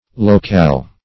Locale \Lo`cale"\, n. [F. local.]